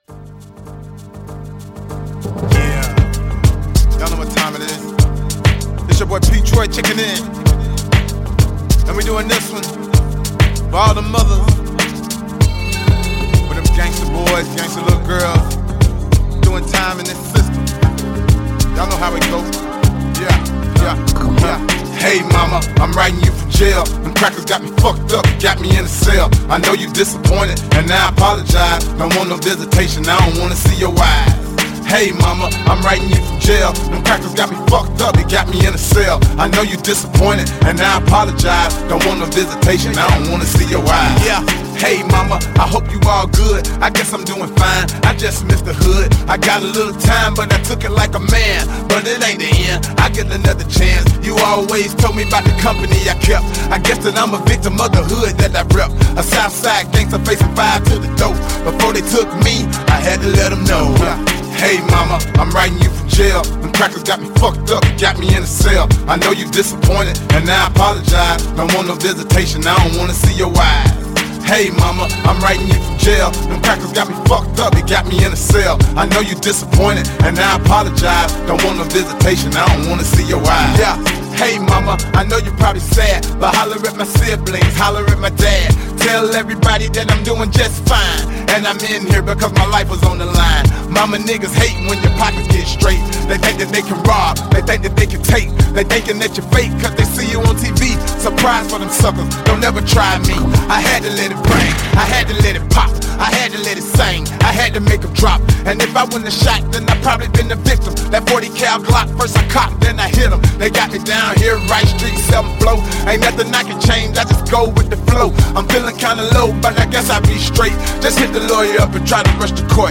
Жанр: Rap, Hip-Hop